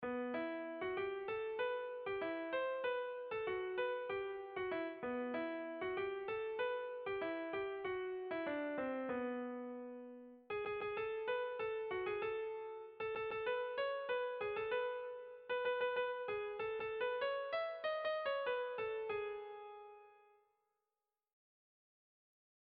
Irrizkoa
Zortziko txikia (hg) / Lau puntuko txikia (ip)
A1A2BD